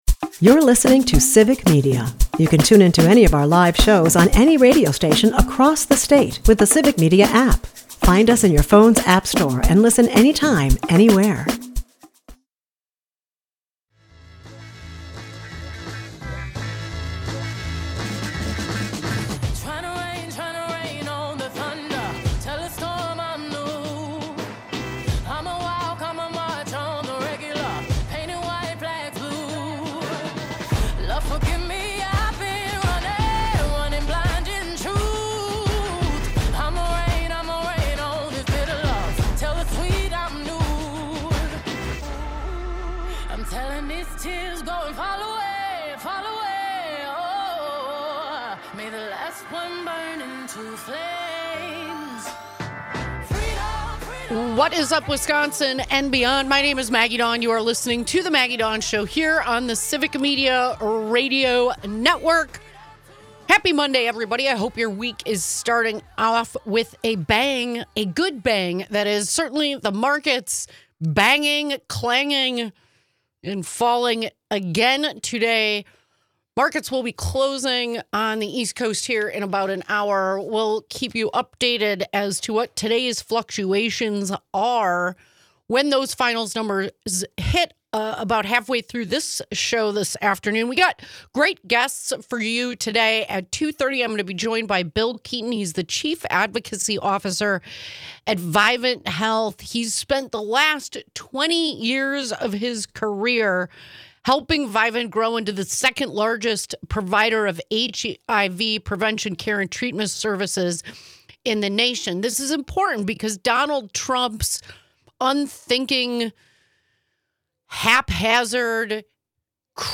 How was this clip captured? We're building a state-wide radio network that broadcasts local news